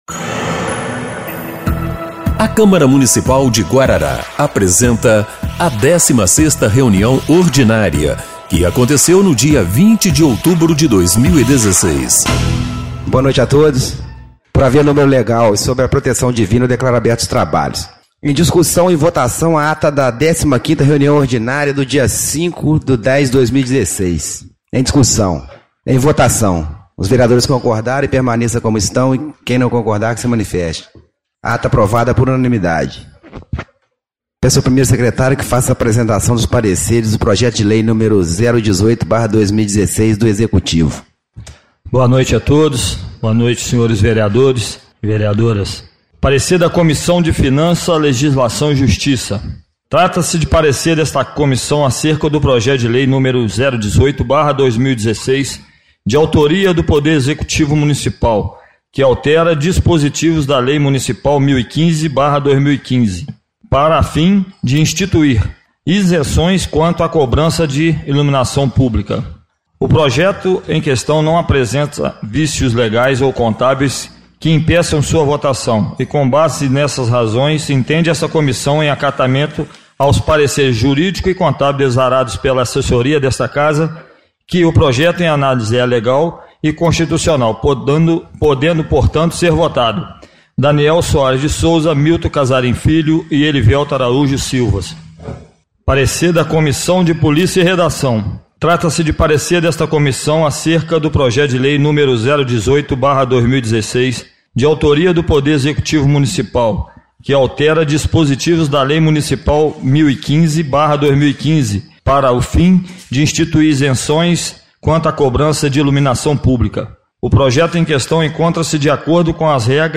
16ª Reunião Ordinária de 20/10/2016